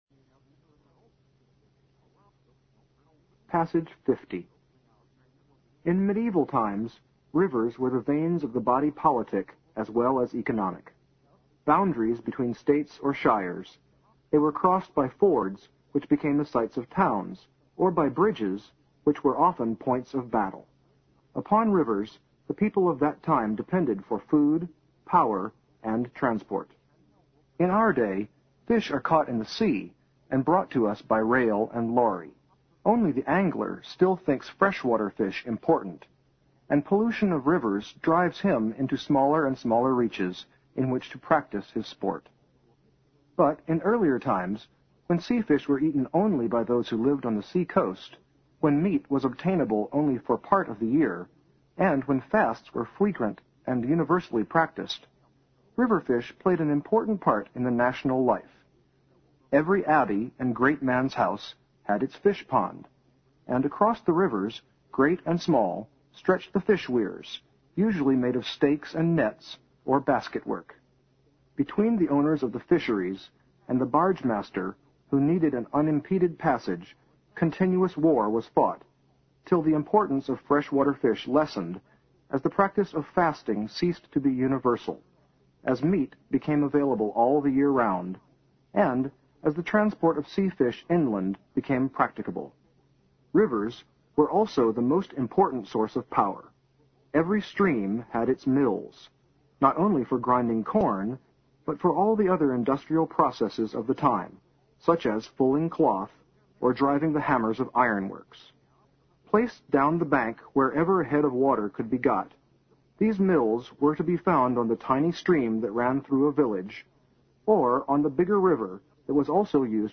新概念英语85年上外美音版第四册 第50课 听力文件下载—在线英语听力室